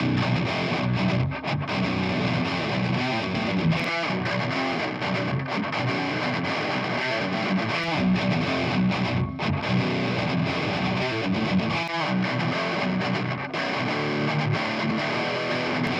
per farvi sentire con metodo A/B continuo come lavora un Eq.